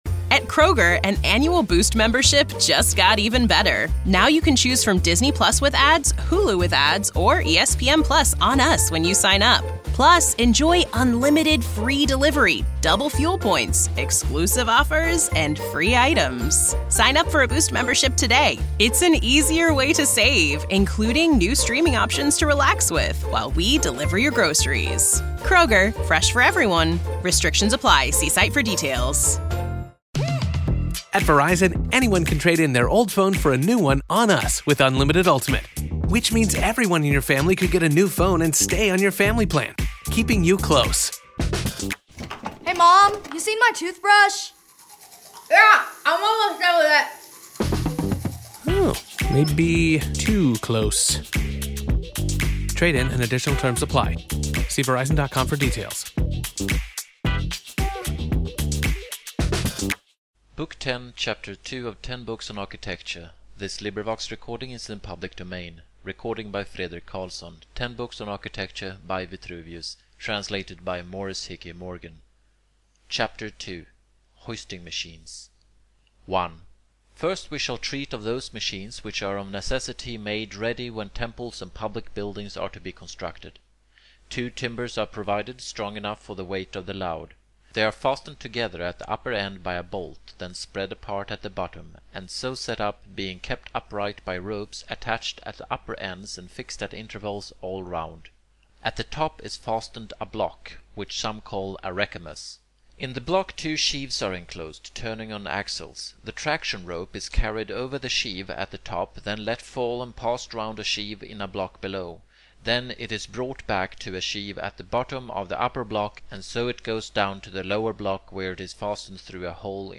100 Great Audiobooks of Literary Masterpieces!